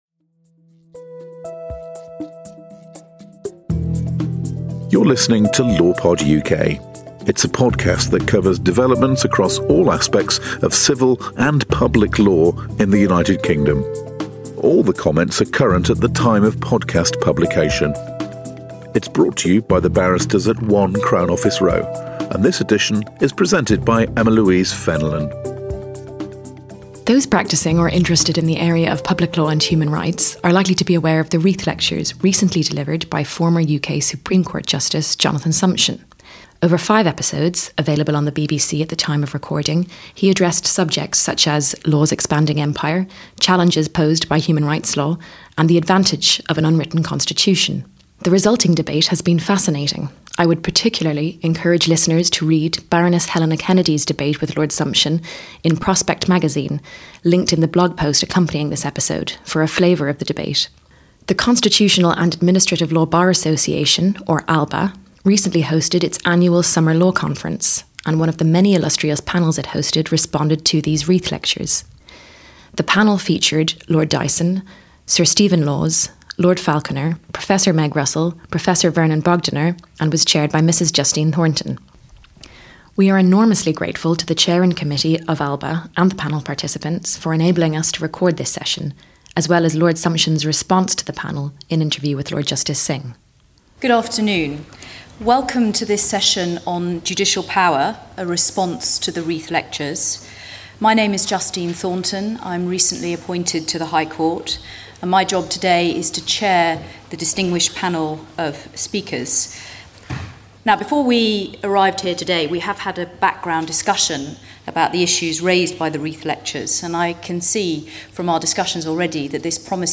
In Episode 88, an esteemed panel of speakers respond to former UK Supreme Court Justice Jonathan Sumption’s Reith Lectures, as part of the Constitutional and Administrative Law Bar Association annual summer law conference.